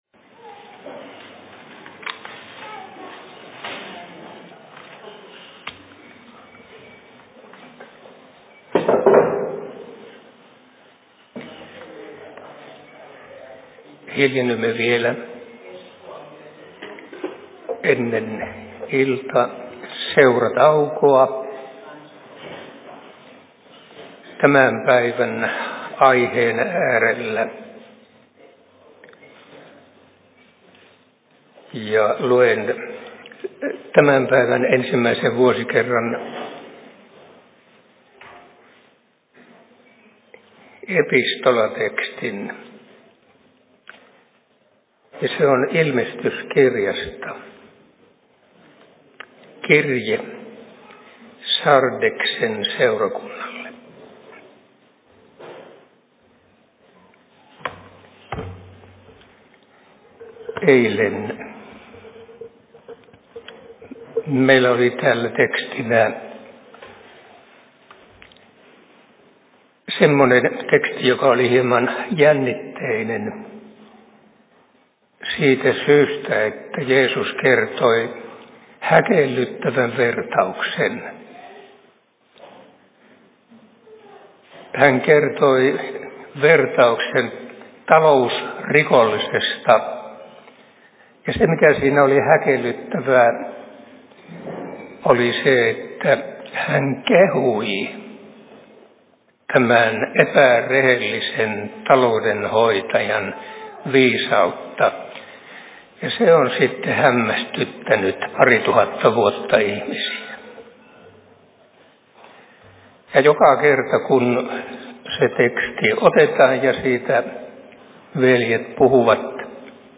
Kesäseurat/Seurapuhe Pateniemen RY:llä 13.08.2023 14.45
Paikka: Rauhanyhdistys Pateniemi